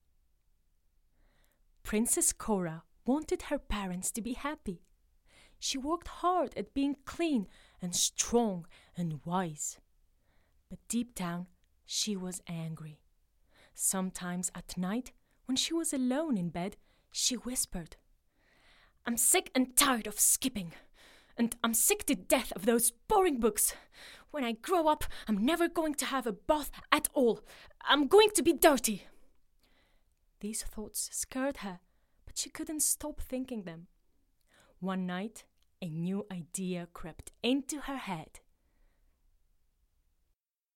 Children's book
- Contralto